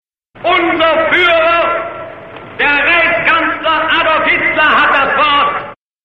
Геббельс Г. представляет Гитлера перед первой его речью на посту райсканцлера, 2 октября 1933
Goebbels_introduces.mp3